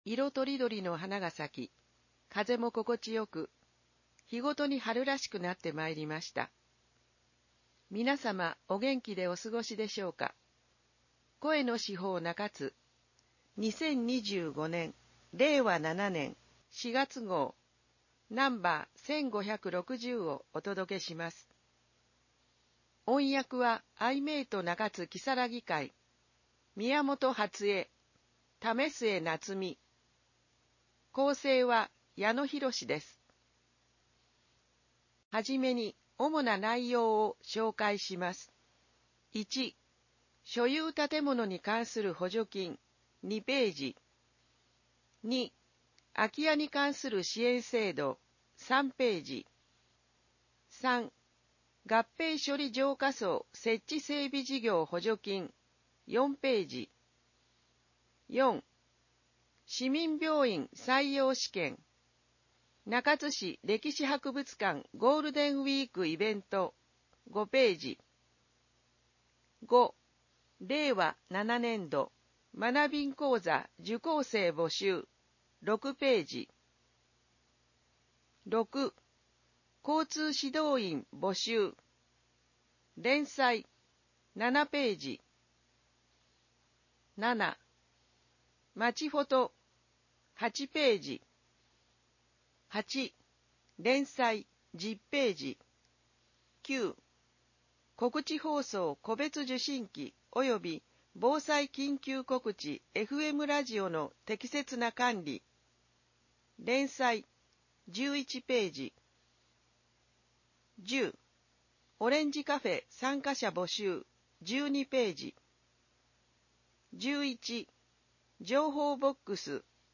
市報の内容を音声で聞くことができます。 アイメイト中津きさらぎ会がボランティアで製作しています。